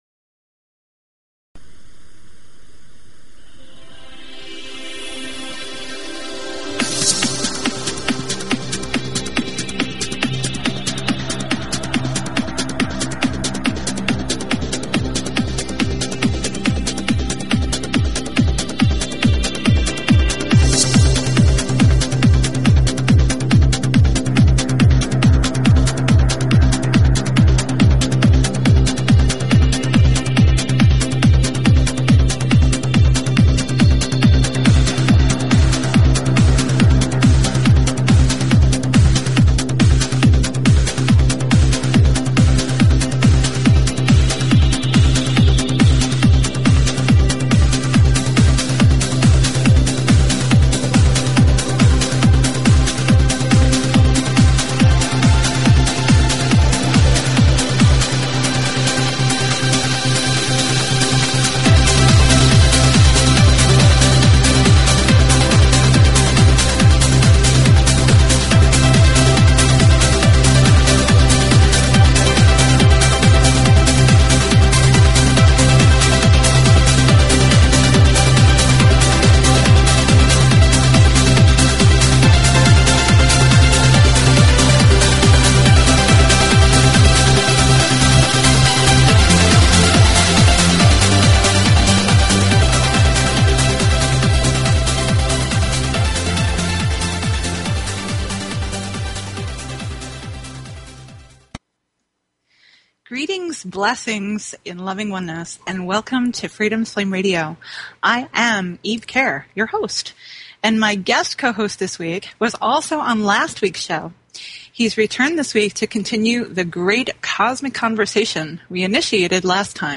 Talk Show Episode, Audio Podcast, Freedoms_Flame_Radio and Courtesy of BBS Radio on , show guests , about , categorized as